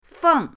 feng4.mp3